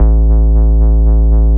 TI100BASS1-R.wav